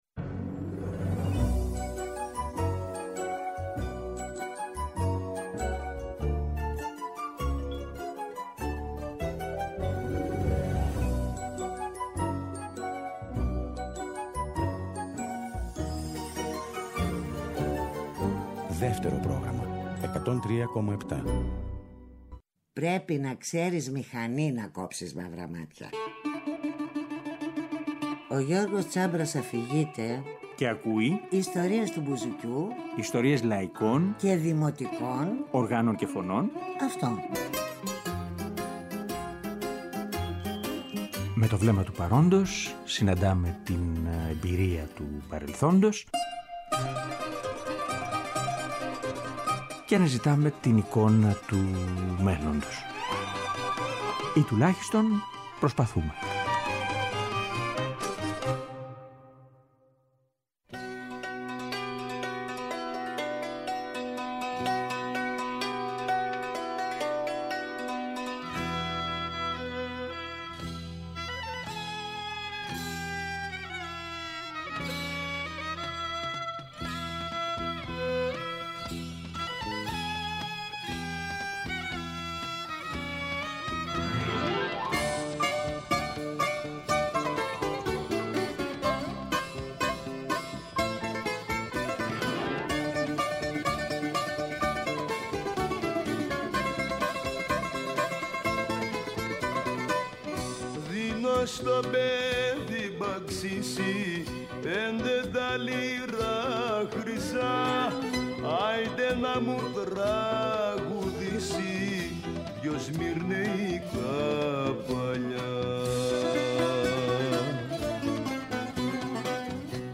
Διασκευασμένες αράβικες μελωδίες